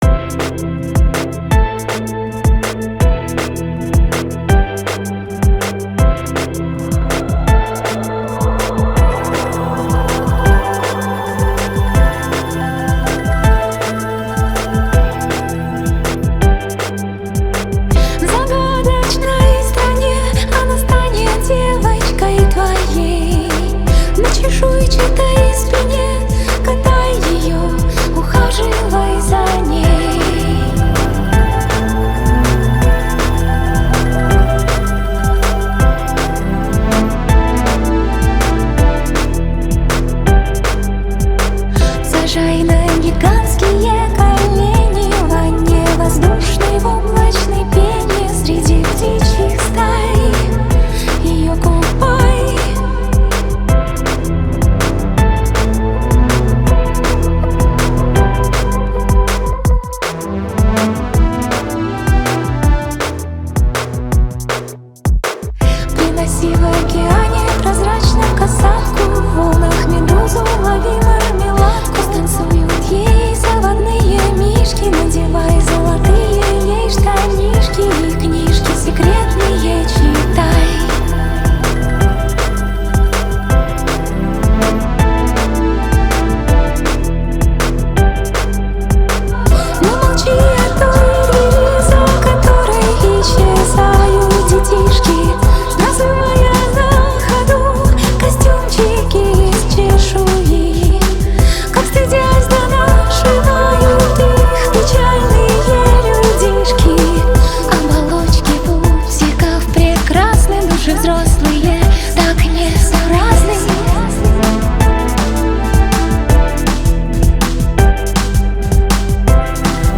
Российский музыкальный дуэт